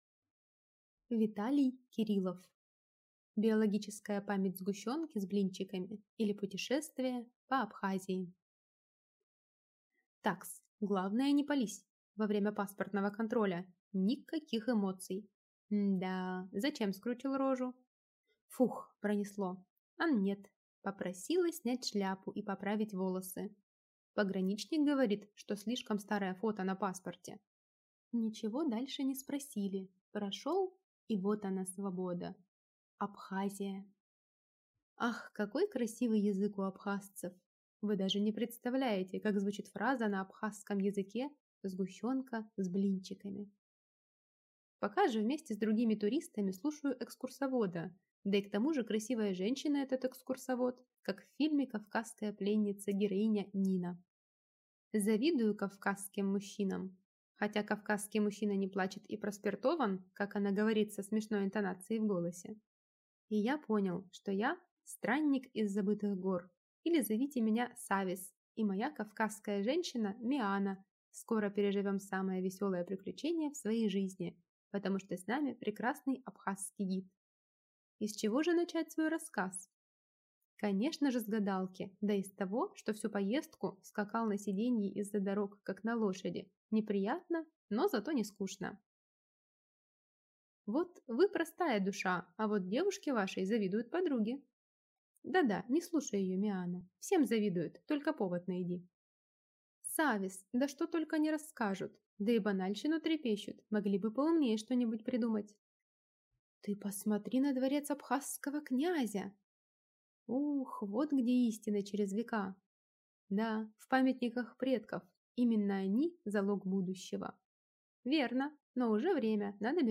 Aудиокнига Биологическая память сгущенки с блинчиками, или Путешествие по Абхазии Автор Виталий Александрович Кириллов Читает аудиокнигу Аудиоагент ЛитРес Чтец.